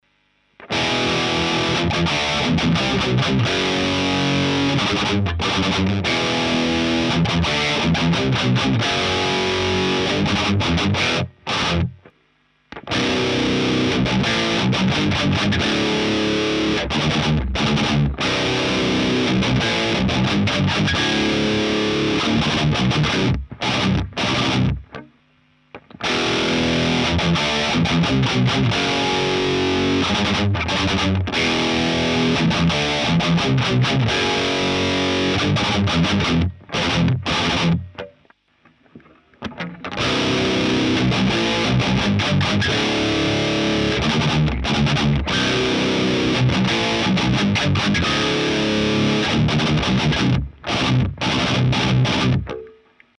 Вот еще сравнение ламп 12ах7/6н2п...: